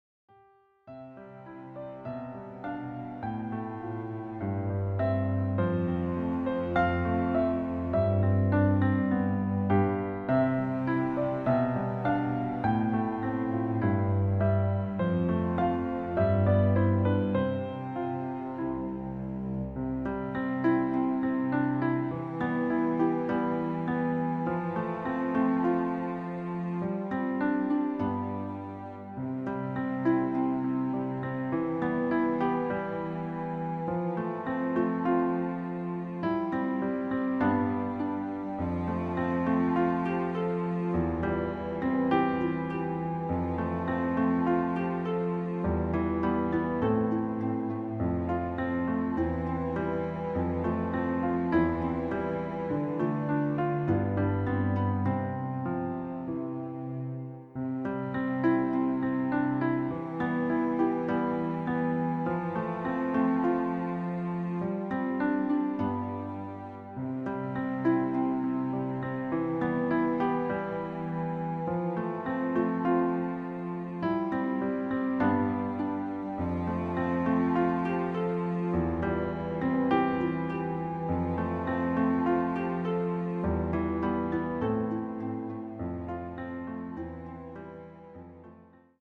• Ablauf: Vers, Vers, zwischenspiel, Vers
• Tonart: Bb-Dur, Ab Dur, C Dur
• Art: Klavier/Streicher
Lediglich die Demos sind mit einem Fade-In/Out versehen.
Klavier / Streicher